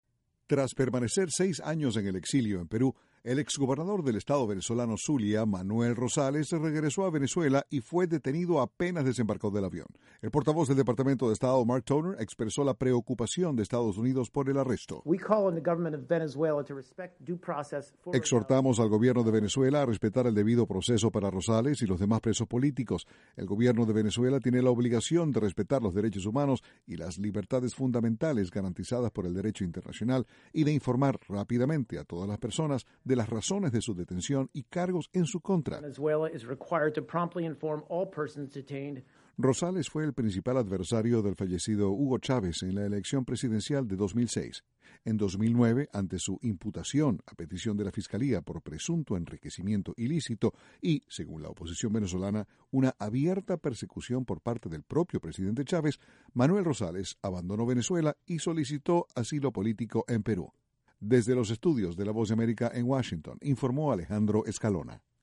El gobierno estadounidense instó a Venezuela a respetar el debido proceso para un ex gobernador detenido a su regreso del exilio. Desde la Voz de América, Washington, informa